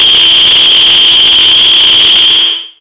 RADIOFX  6-L.wav